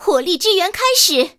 野蜂开火语音2.OGG